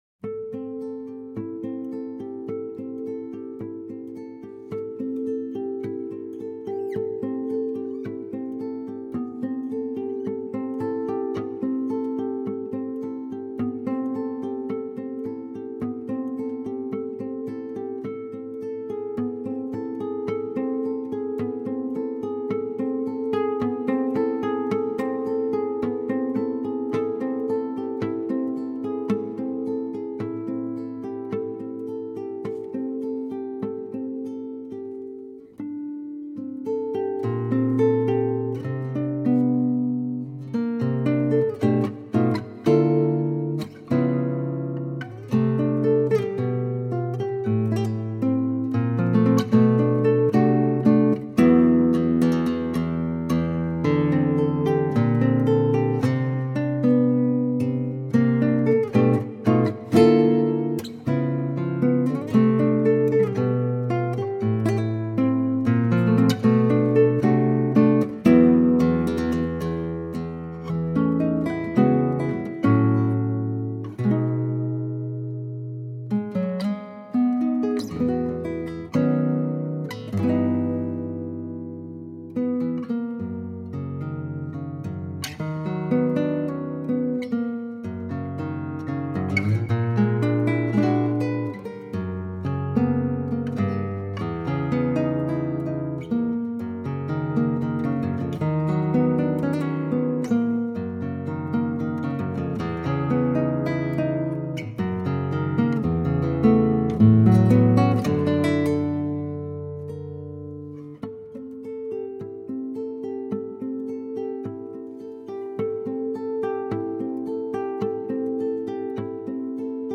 Instrumentação: violão solo
Tonalidade: Dm | Gênero: instrumental brasileiro